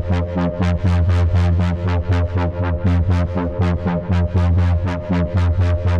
Index of /musicradar/dystopian-drone-samples/Tempo Loops/120bpm
DD_TempoDroneA_120-F.wav